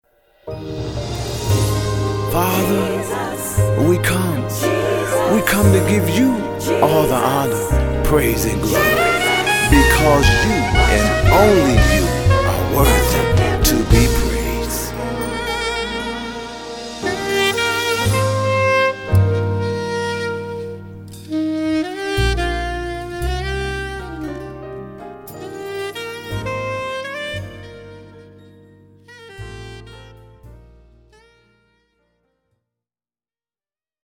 Gospel jazz